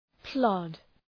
Προφορά
{plɒd}